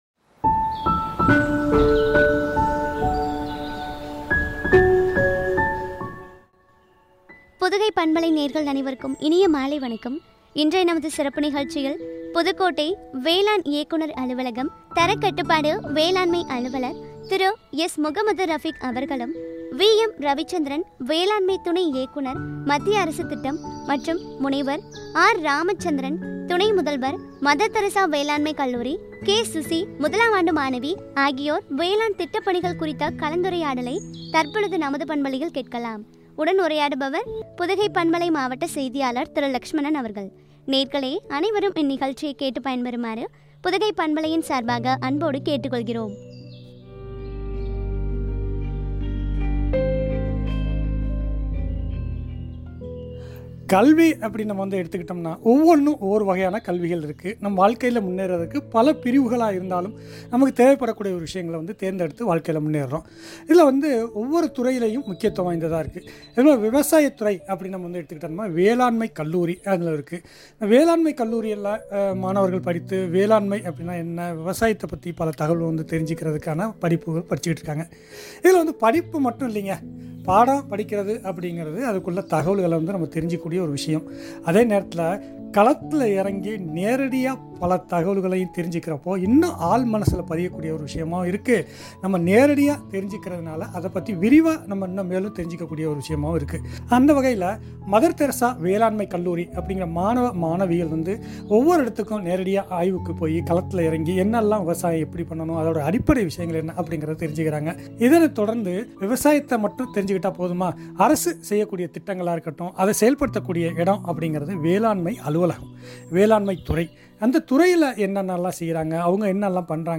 வேளாண் திட்ட பணிகள் மாணவர்களுடன் கலந்துரையாடல்